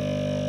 New floppy sound samples